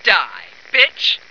flak_m/sounds/female2/int/F2diebitch.ogg at trunk